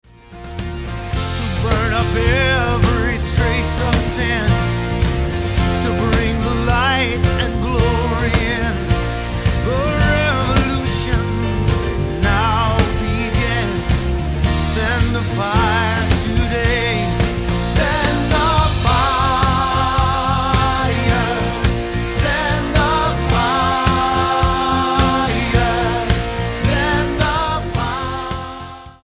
Style: Pop Approach: Praise & Worship